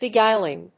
音節be・guíl・ing 発音記号・読み方
/bɪˈgaɪlɪŋ(米国英語)/